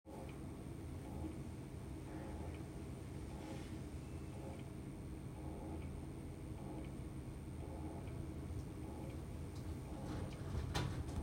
PC macht komisches Geräusch?
Hallo, mein PC macht ein komisches Geräusch. Kann dies von einer SSD sein?